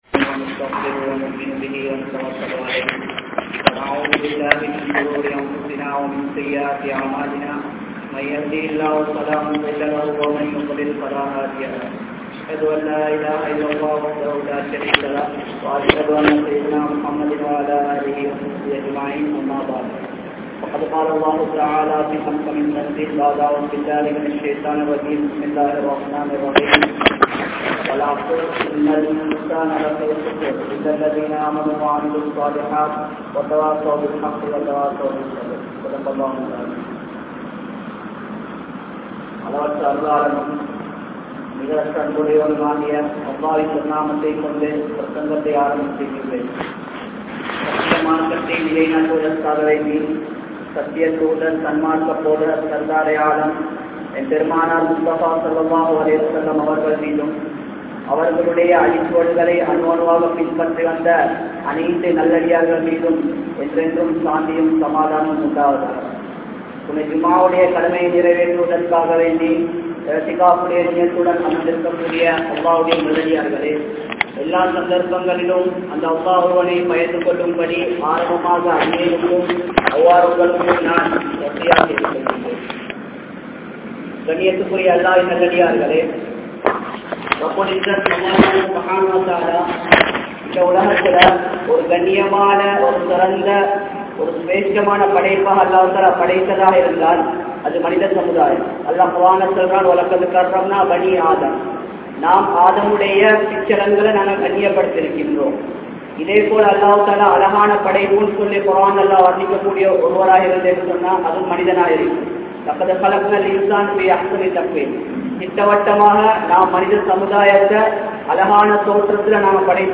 Mannaraien Soathanai (மண்ணறையின் சோதனை) | Audio Bayans | All Ceylon Muslim Youth Community | Addalaichenai
Badhuriya Jumua Masjidh